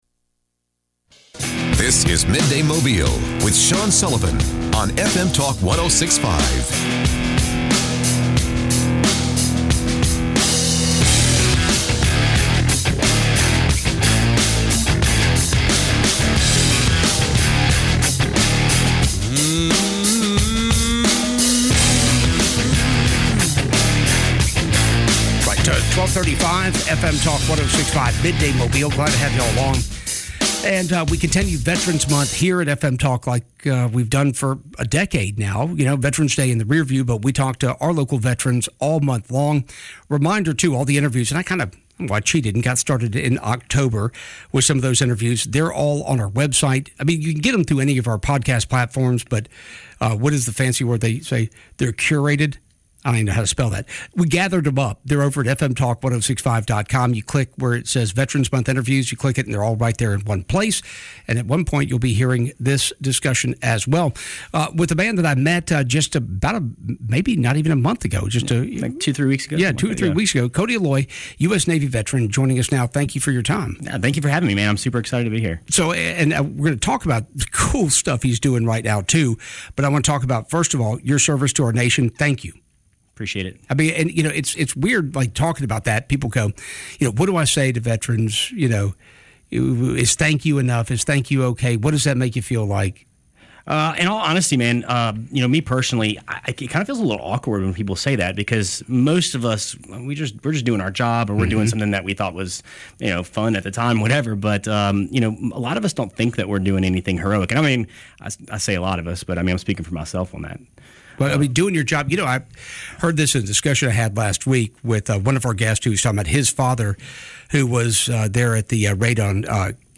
Veteran Interview